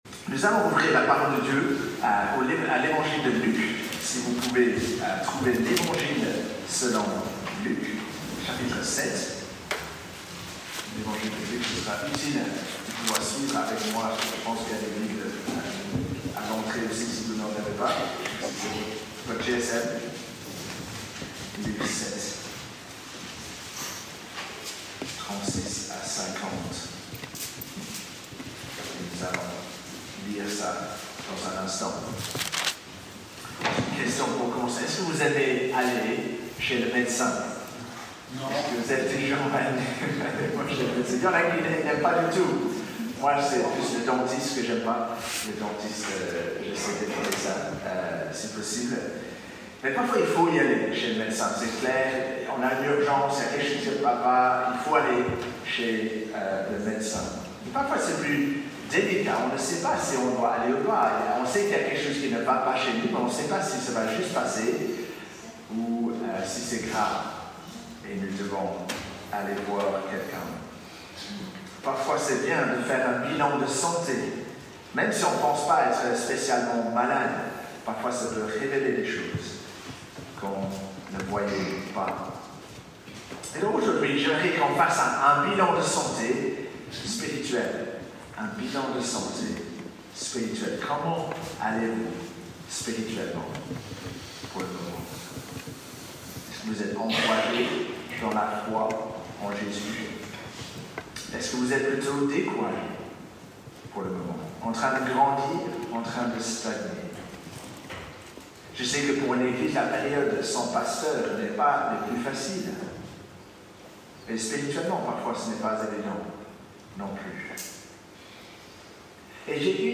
Ecouter le message
Suite à un problème technique, la qualité sonore de l’enregistrement est exceptionnellement mauvaise.